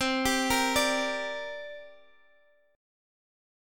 Listen to C7sus2 strummed